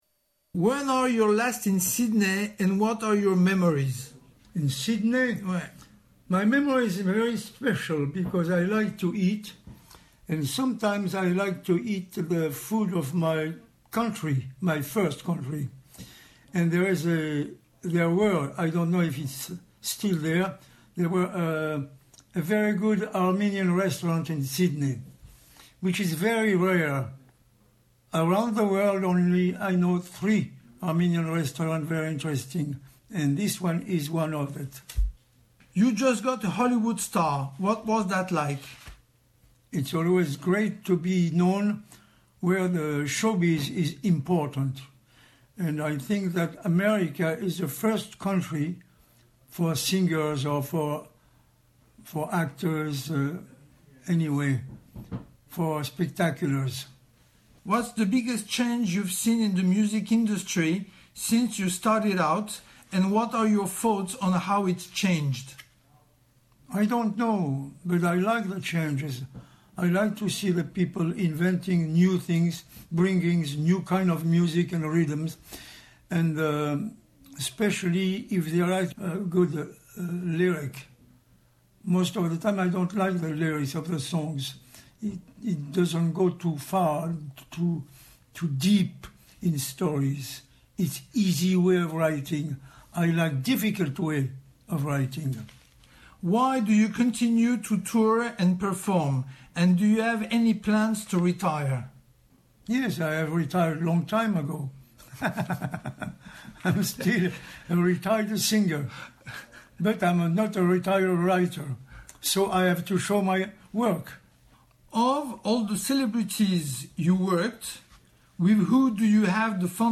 Charles Aznavour - the legendary singer's last interview with SBS Radio